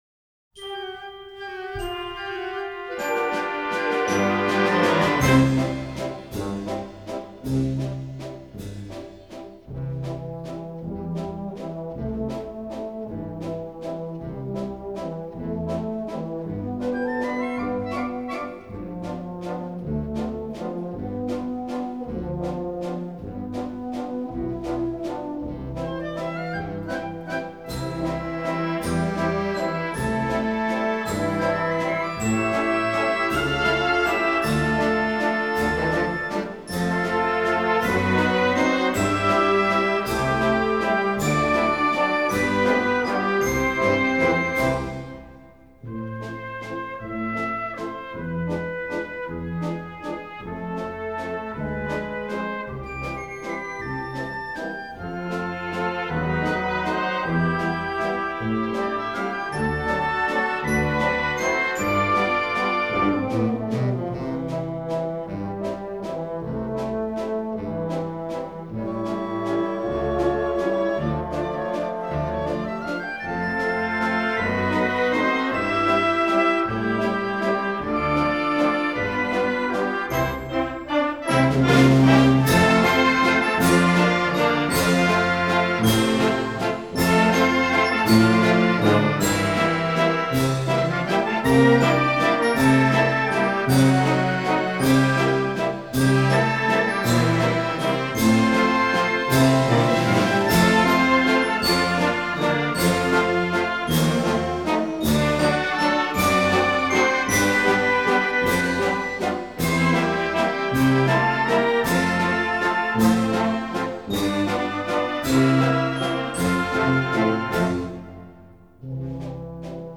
Wienervalssin musiikki